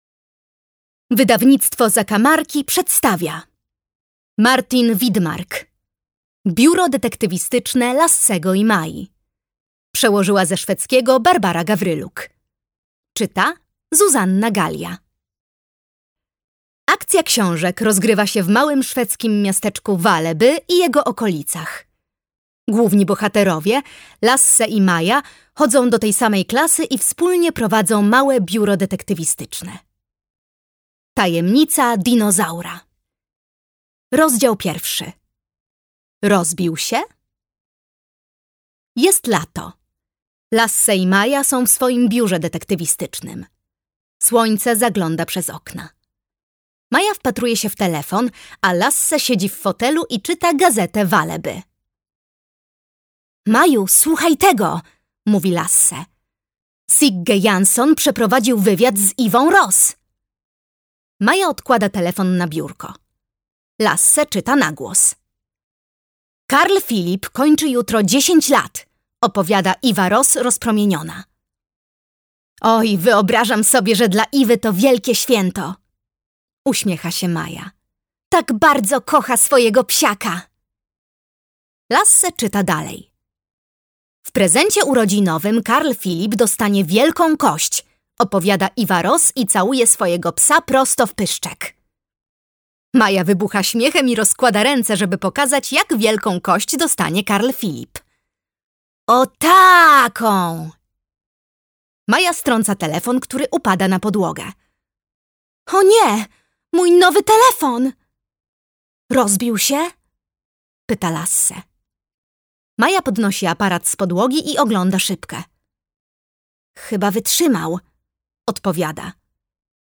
Biuro Detektywistyczne Lassego i Mai. Tajemnica dinozaura - Martin Widmark - audiobook